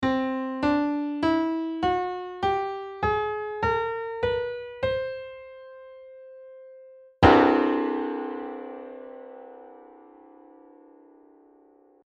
The otonal scale of harmonics 8-16 in 45edo flattone.
Note the inconsistency where 16/15 is larger than 15/14 and the use of the second best approximation of the 13th harmonic.
45EDO_Otonal.mp3